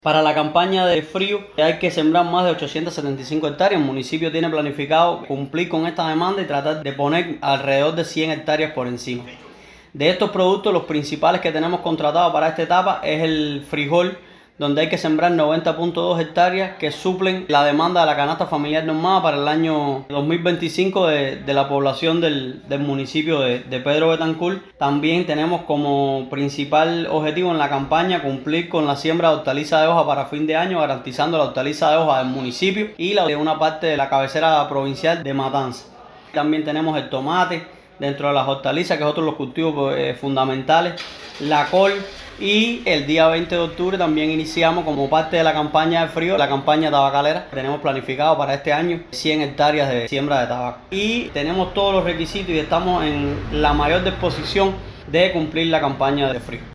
Al respecto conversamos con Oslay Díaz Pagés, viceintendente de Alimentos del poblado, quien reconoció el compromiso de la máxima dirección territorial con el desarrollo agropecuario y el apoyo a los productores betancourenses, al tiempo que destacó los logros alcanzados en la campaña de primavera previa.